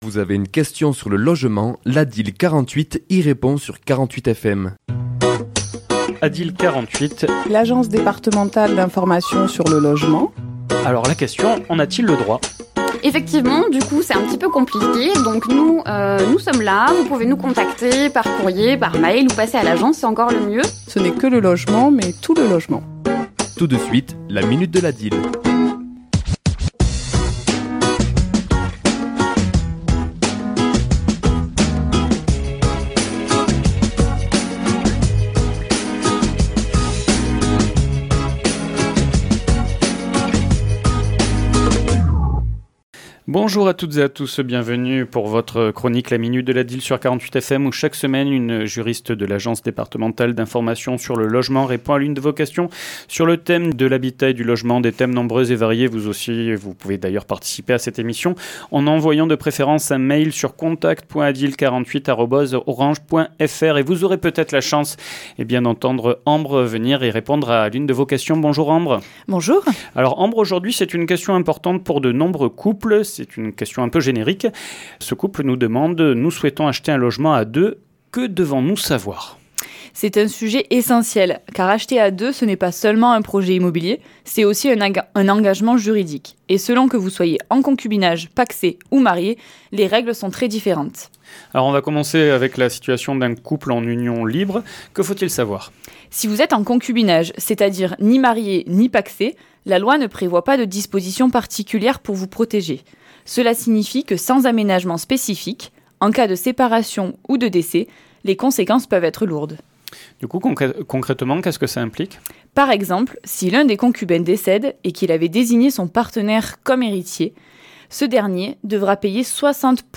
Chronique diffusée le mardi 15 avril à 11h et 17h10